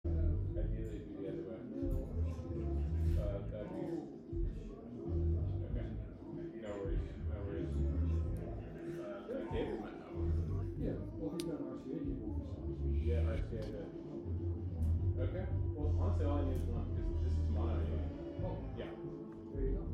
Live from Woodstockhausen Music Festival
Live from Woodstockhausen Music Festival: Woodstockhausen 25' West (Audio) Aug 29, 2025 shows Live from Woodstockhausen Music Festival Woodstockhausen is a bi-coastal experimental music festival.